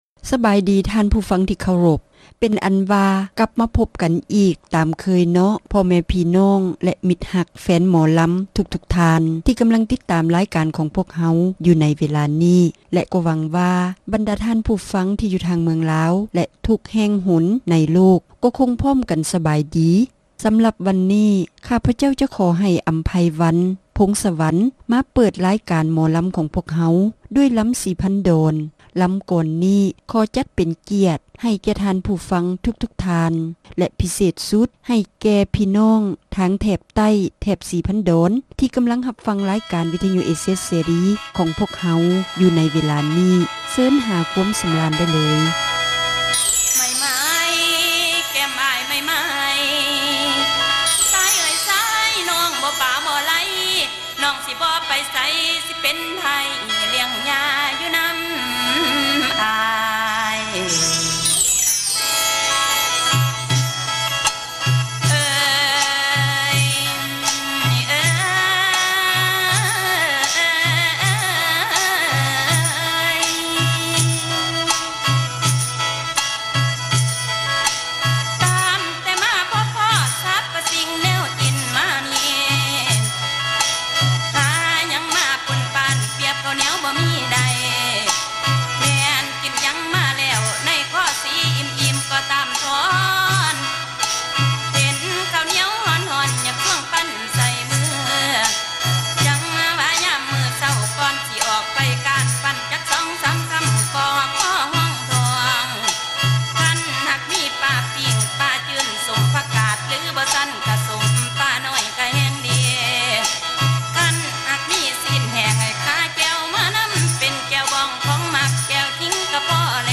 ຣາຍການໜໍລຳ ປະຈຳສັປະດາ ວັນທີ 6 ເດືອນ ກໍຣະກະດາ ປີ 2007